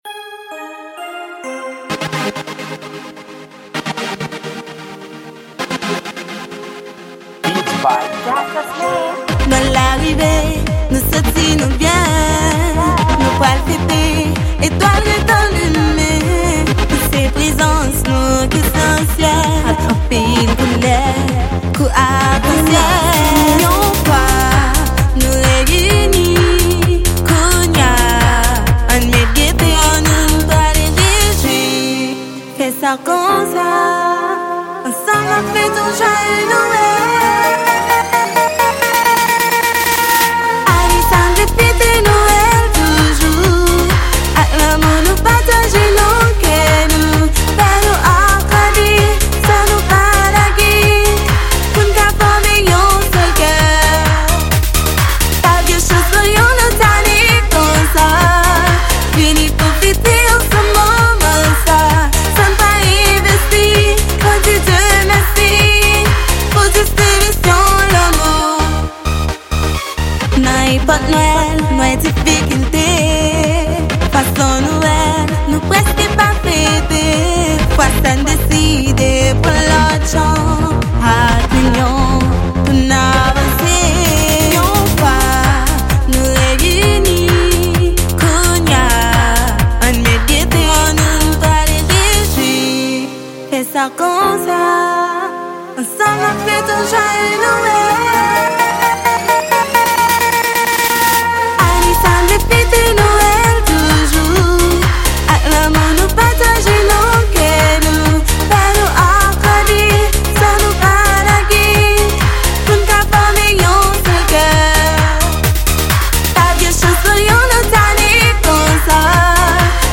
Genre: WORLD